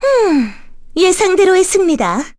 Chrisha-Vox_Victory_kr.wav